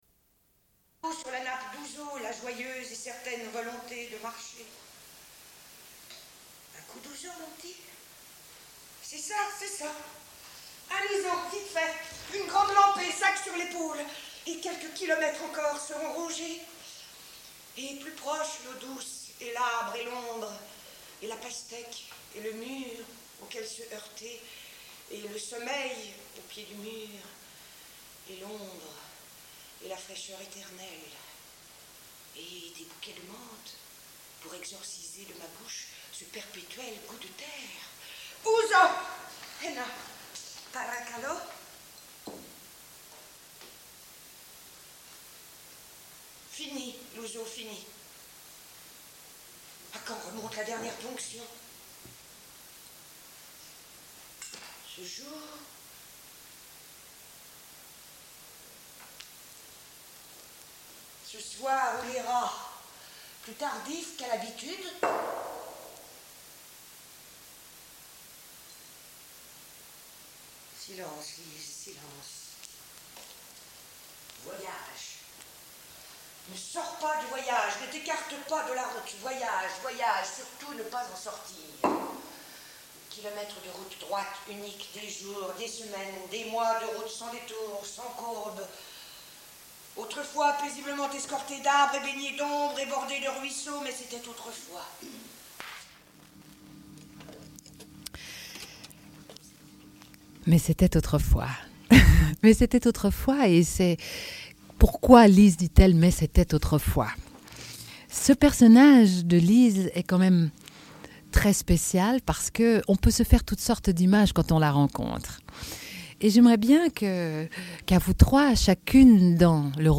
Une cassette audio, face B28:28